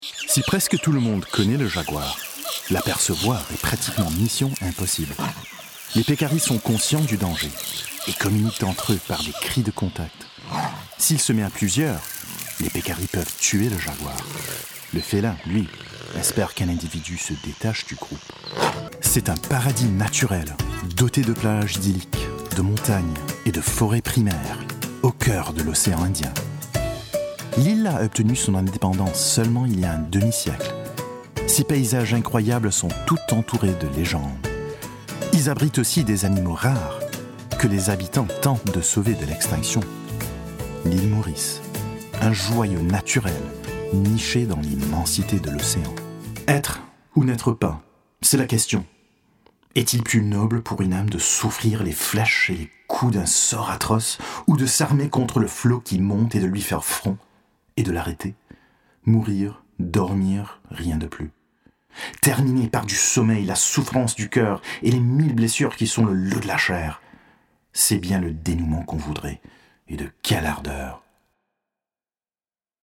Foreign Language Voice Samples
Commercial Demo
Baritone
WarmFriendlyReliableAssuredEngaging